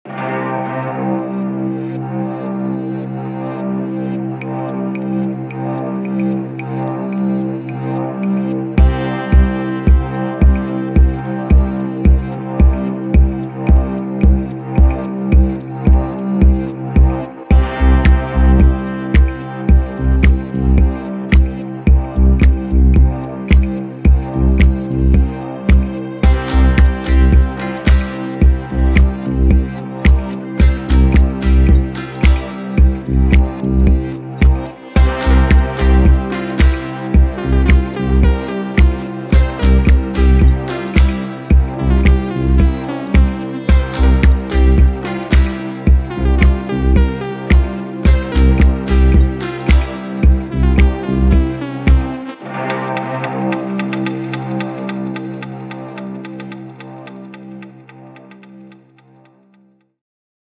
Indie Electronic